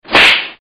打耳光.mp3